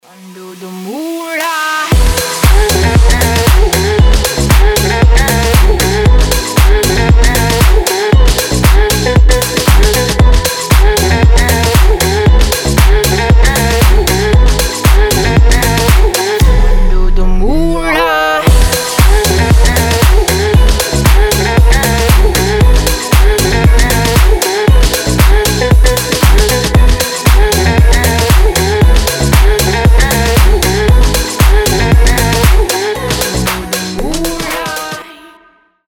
• Качество: 320, Stereo
deep house
Electronic
Club House
космические